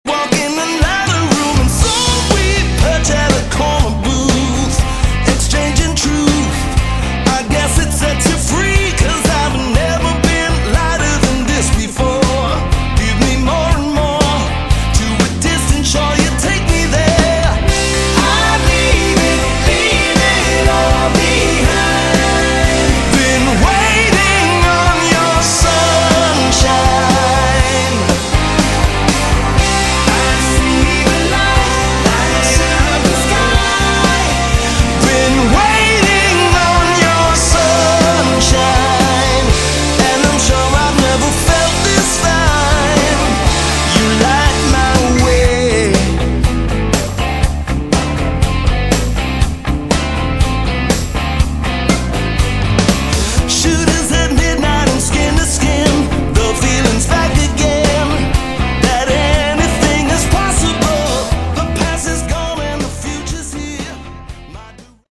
Category: AOR / Melodic Rock
vocals, keyboards, guitars